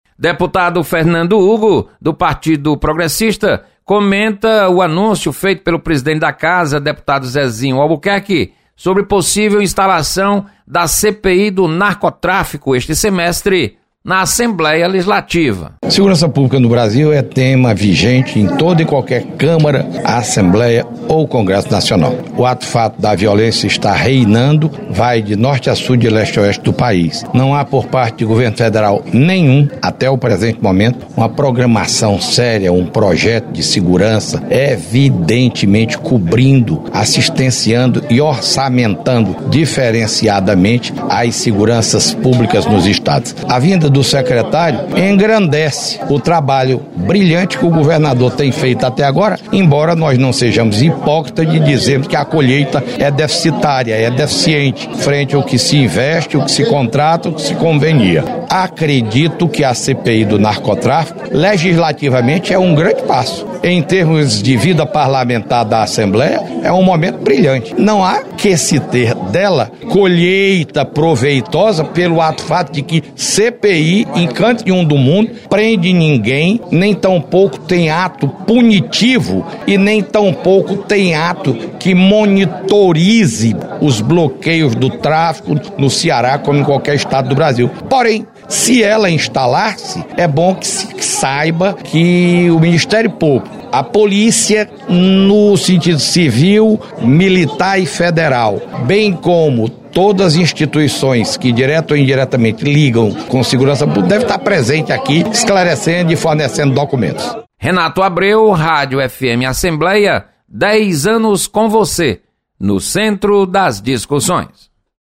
Deputado Fernando Hugo comenta sobre possível instalação de CPI do Narcotráfico.